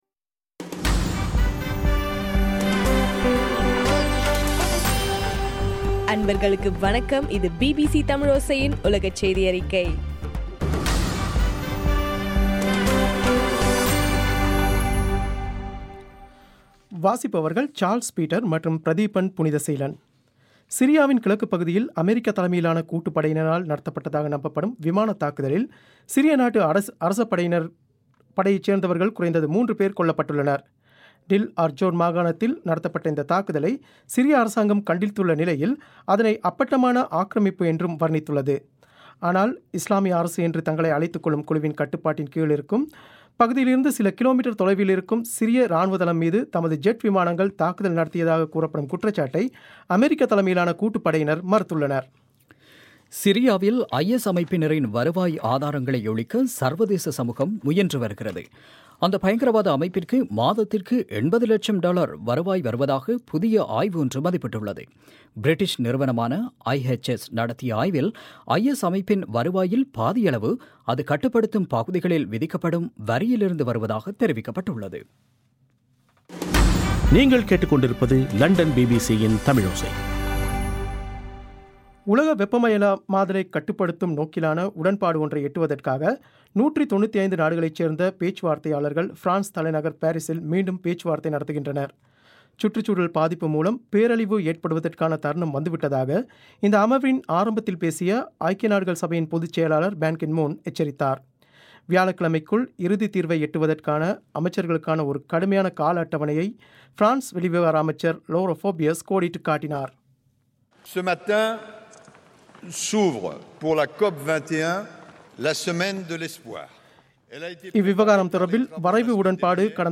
இன்றைய ( டிசம்பர் 7) பிபிசி தமிழோசை செய்தியறிக்கை